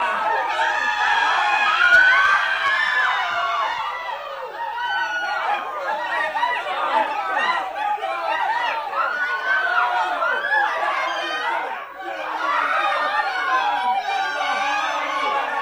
Звук панических воплей в переполненном зале